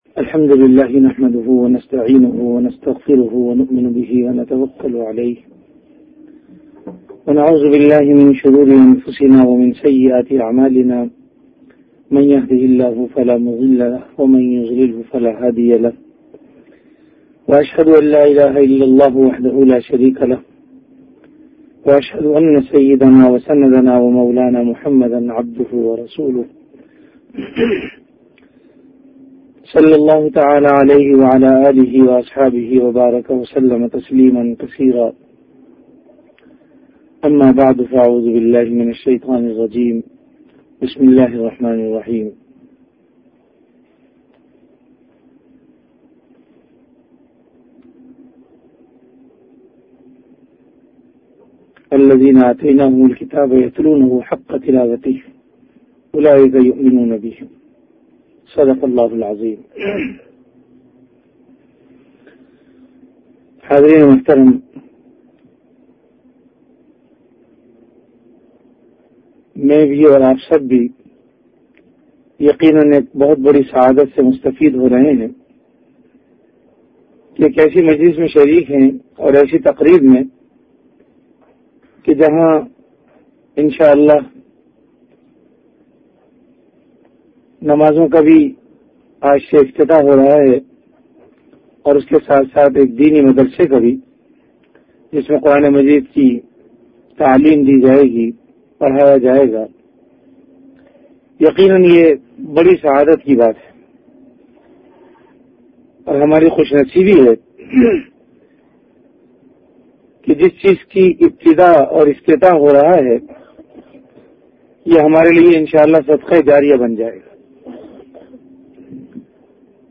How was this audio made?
Delivered at Darululoom Shafiq ul Islam.